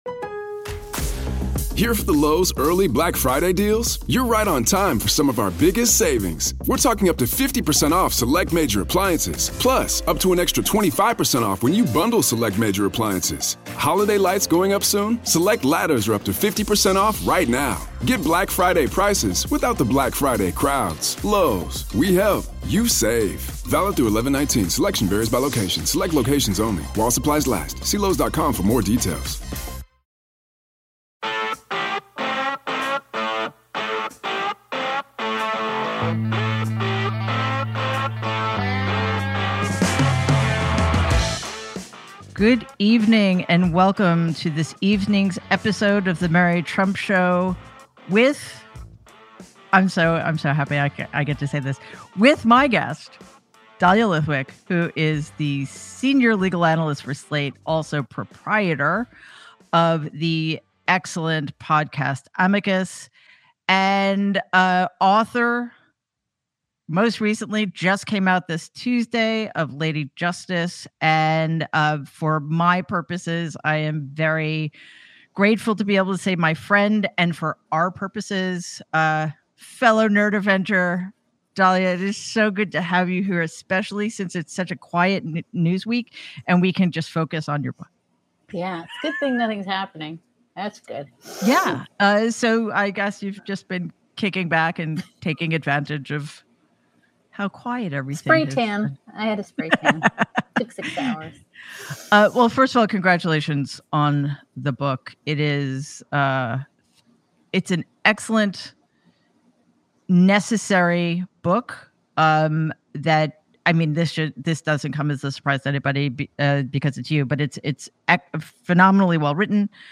Mary Trump is joined by author and legal expert Dahlia Lithwick for a conversation about the catastrophic state of our institutions and whether it’s worth working within the system to reform them.